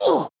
damage2.wav